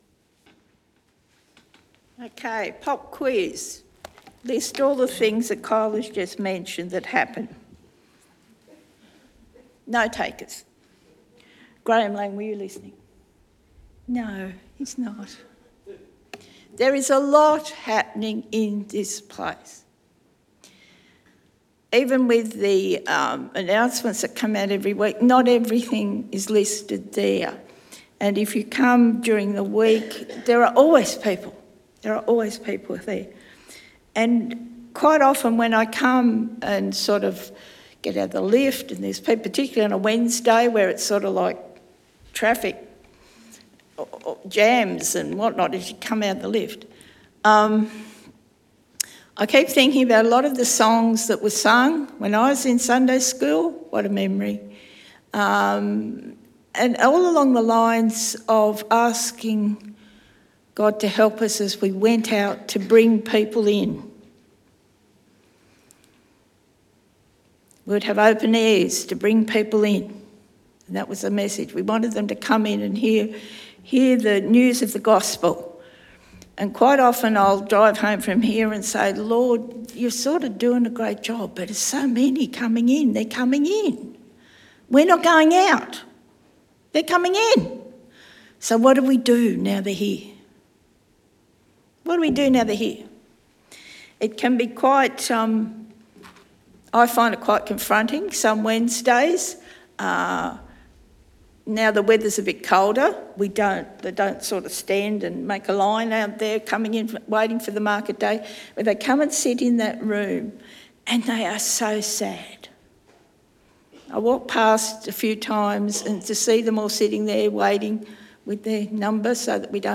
Sermon Podcasts Greater Things